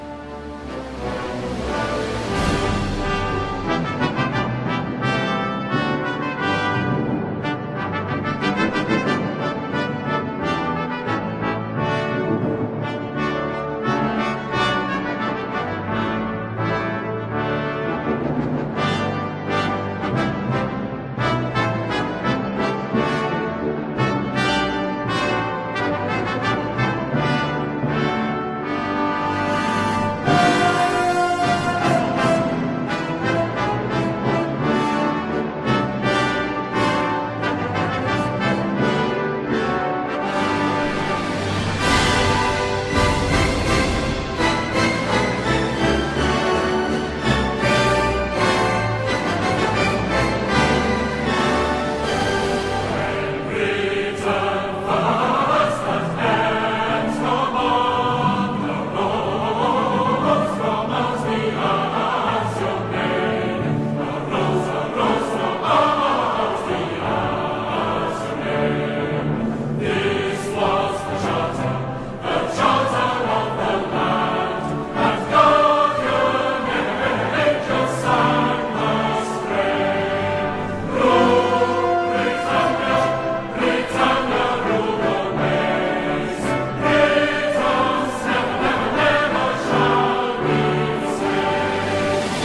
british-patriotic-song_-rule-britannia-mp3cut.net_.m4a